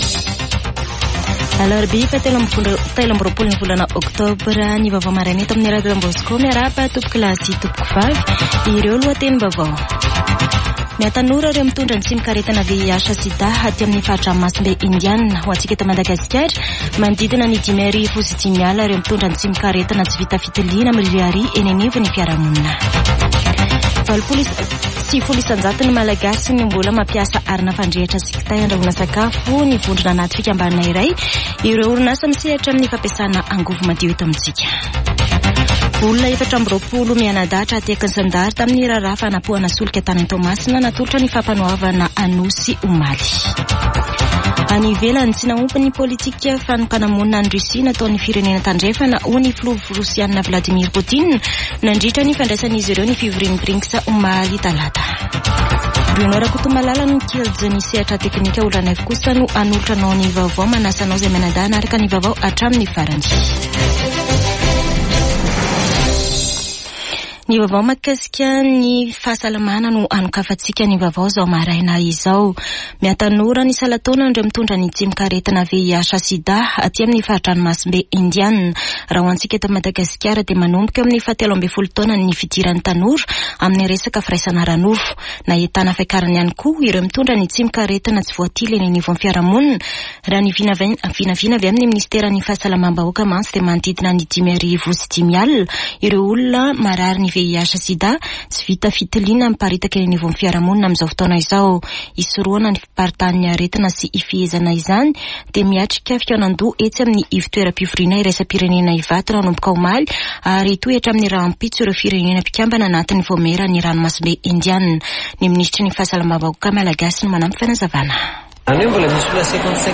[Vaovao maraina] Alarobia 23 oktobra 2024